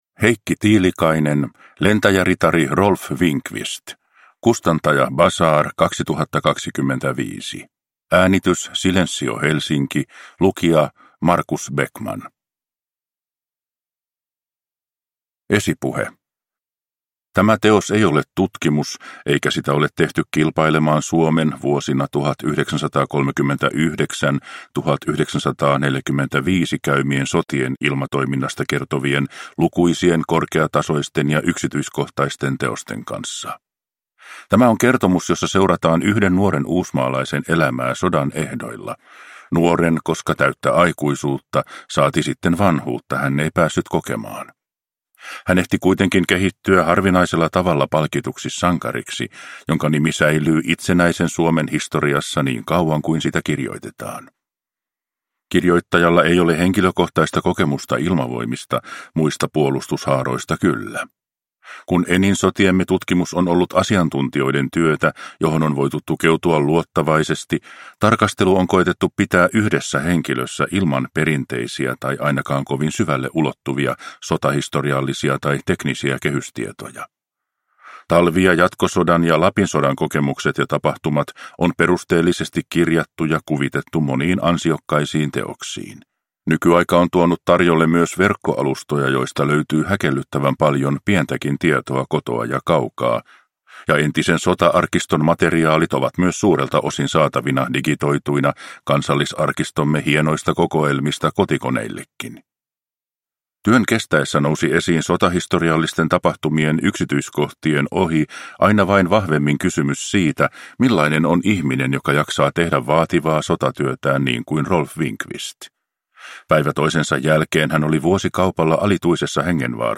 Lentäjäritari Rolf Winqvist – Ljudbok